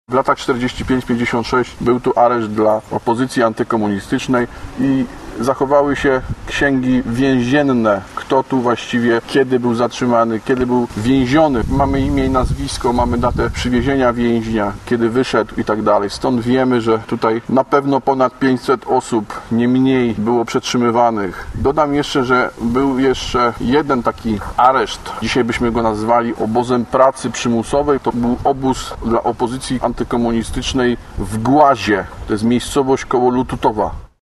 podczas prezentacji piwnicy budynku przy ulicy Częstochowskiej w Wieluniu.